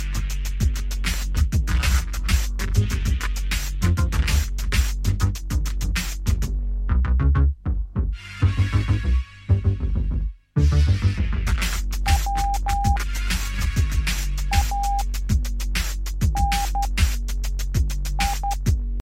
Electro